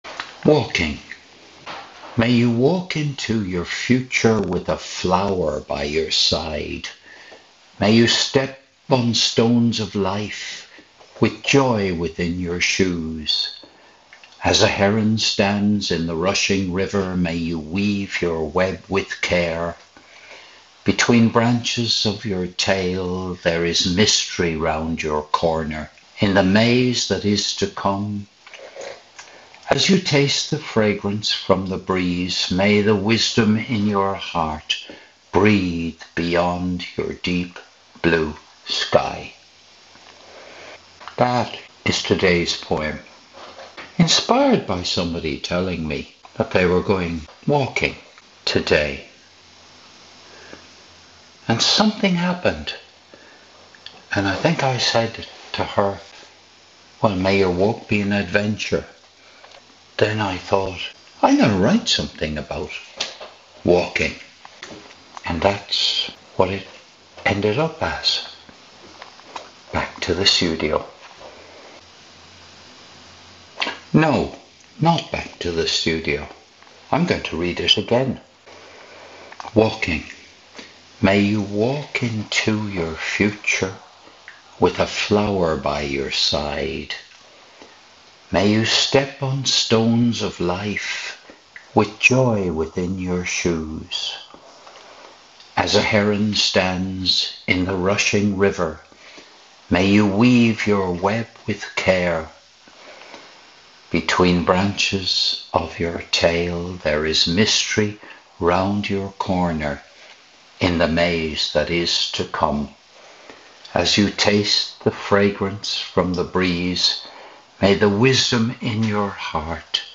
This was recorded in the restaurant of CobhGolf Club as about 930am on Wednesday seventh of May 2025. I was having a cup of tea before going to practice putting.